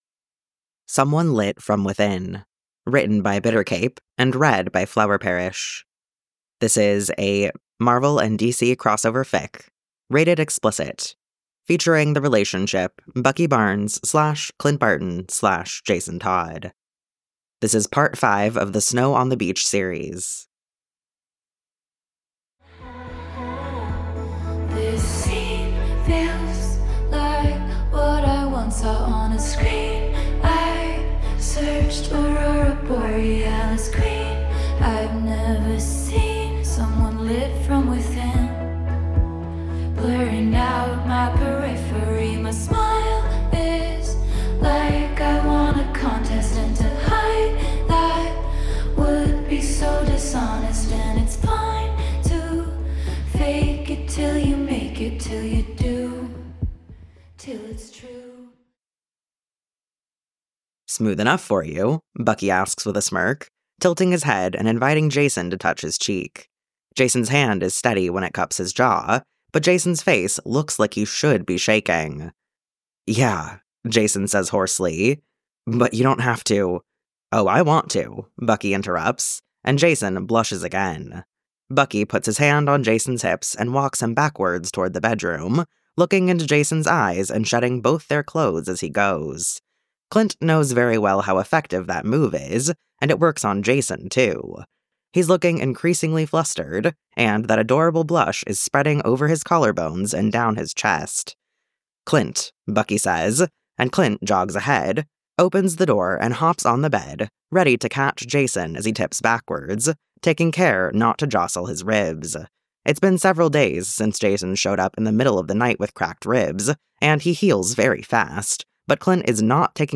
reader